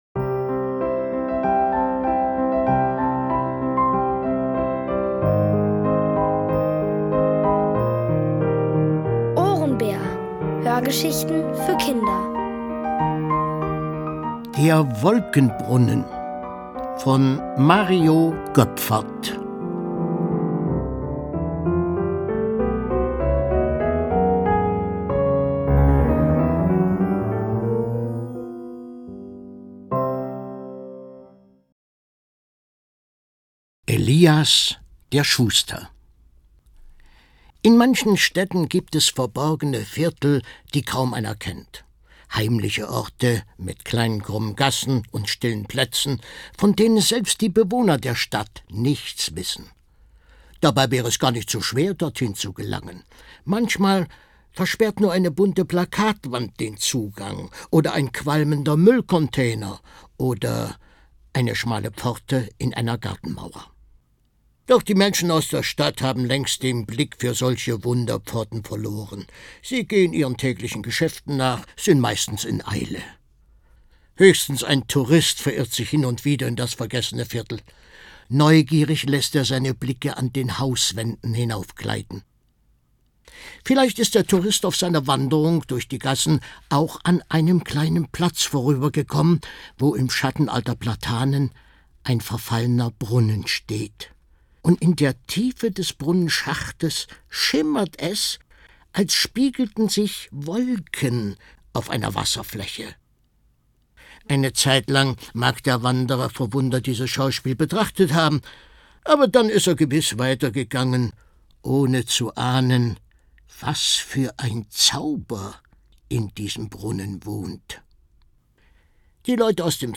Von Autoren extra für die Reihe geschrieben und von bekannten Schauspielern gelesen.
Es liest: Horst Bollmann.